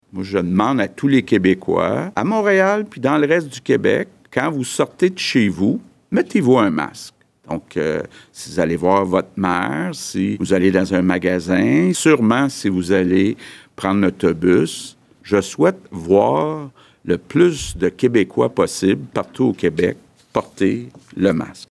Le premier ministre du Québec François Legault est arrivé à son point de presse quotidien muni d’un masque au visage, tout comme la ministre de la Santé Danielle McCann et le Dr Arruda.